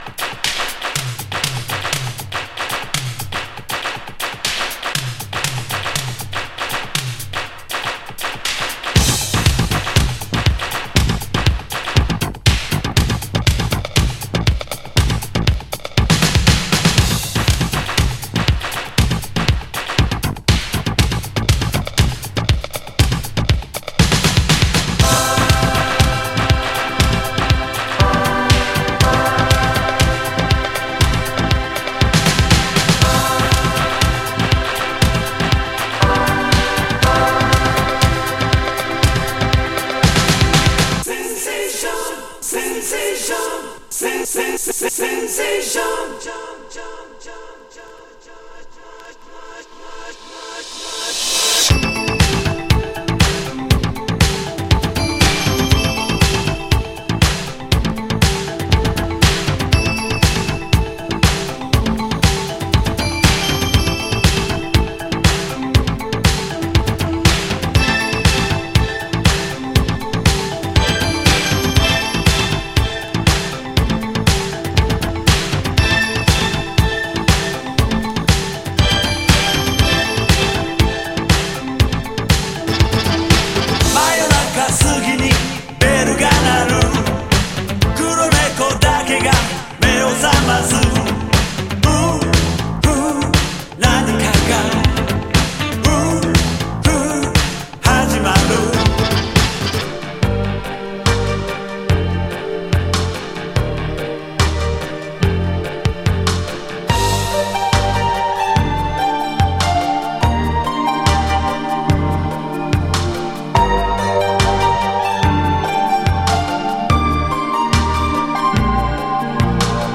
和製ミュータント・エレクトロ・ディスコ